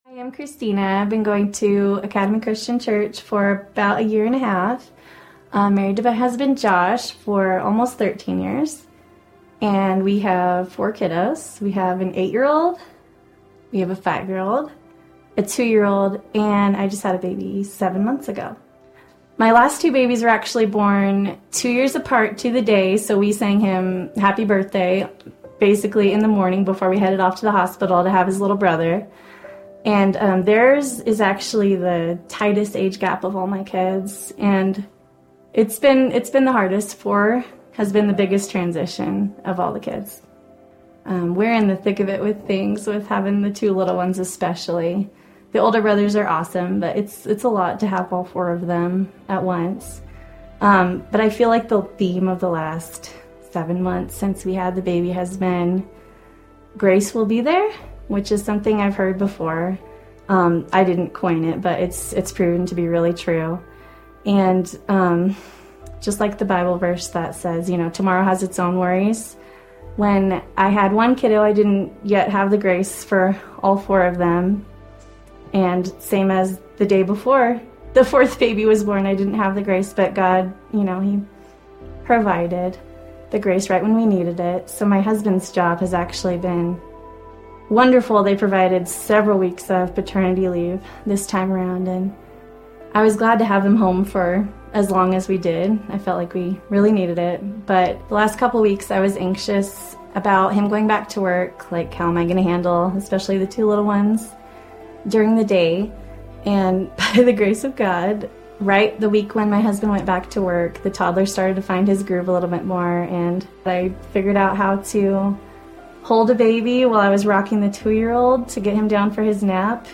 A collection of ACC Sunday Messages that are not a part of a Sermon Series